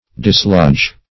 dislodge.mp3